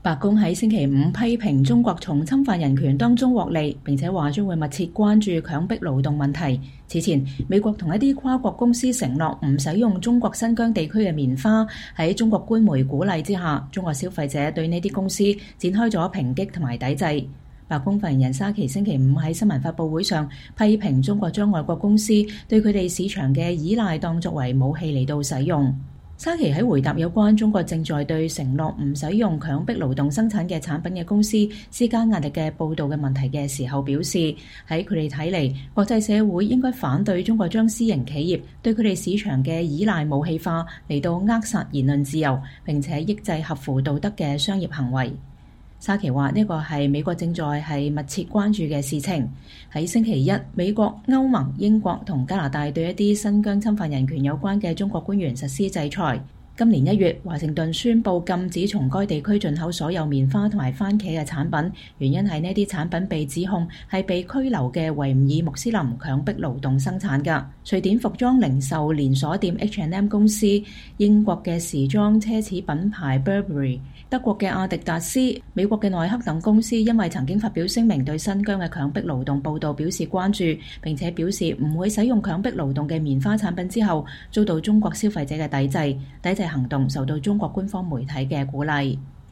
白宮發言人莎琪在白宮簡報會上回答記者提問。